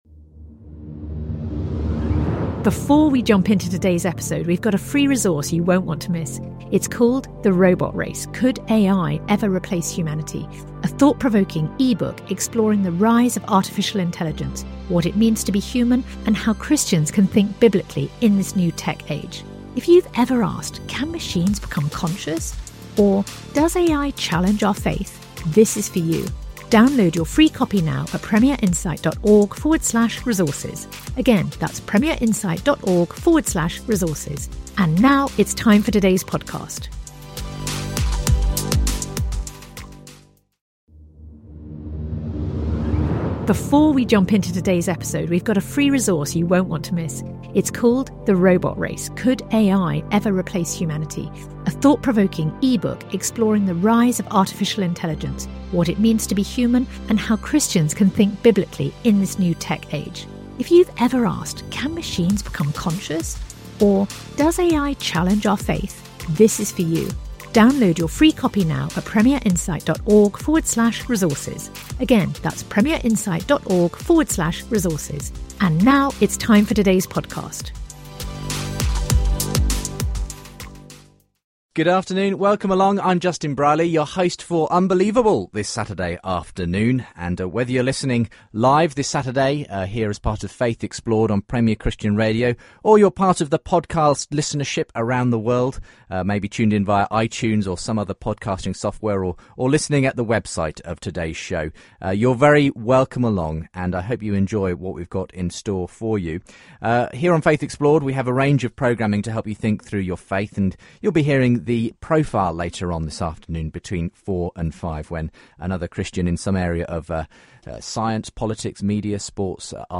Christianity, Religion & Spirituality 4.6 • 2.3K Ratings 🗓 22 August 2014 ⏱ 81 minutes 🔗 Recording | iTunes | RSS 🧾 Download transcript Summary A discussion on whether the Western church has lost the Hebraic roots of its faith and why anti semitism has been a factor in the church.